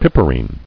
[pip·er·ine]